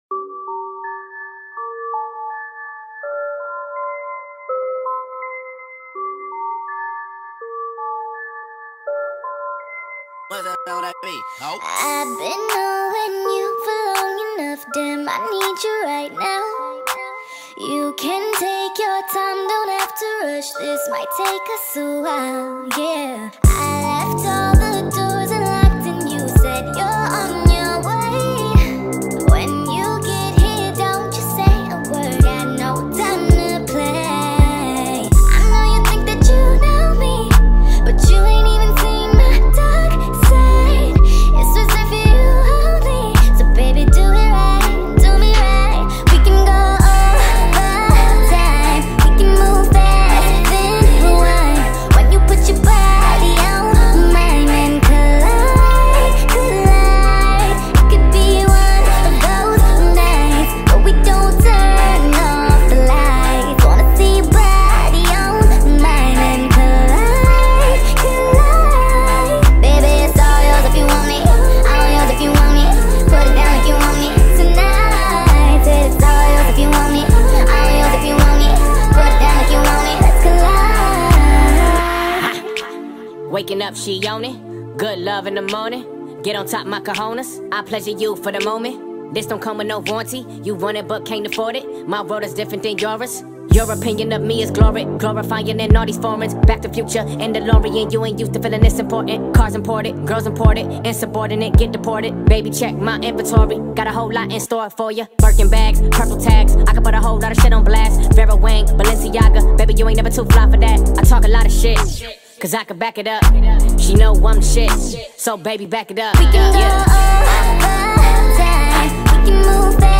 sped up remix